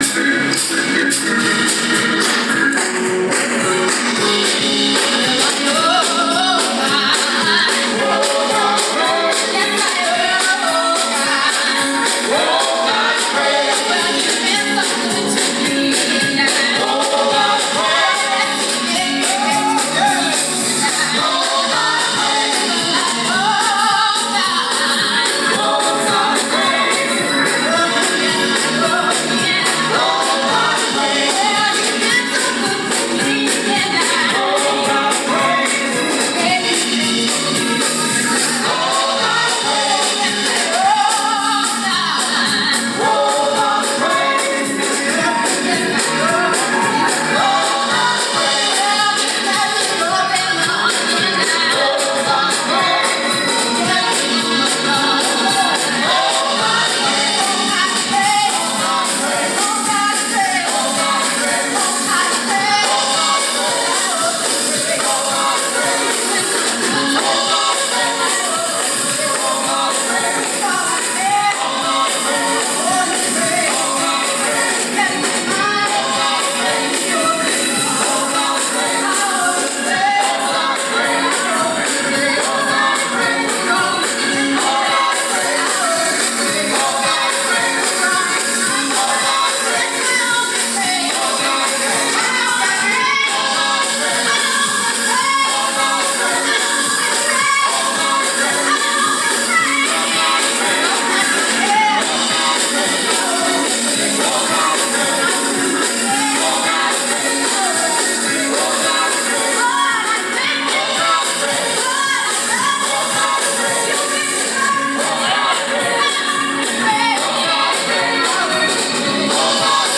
Congregational
Gospel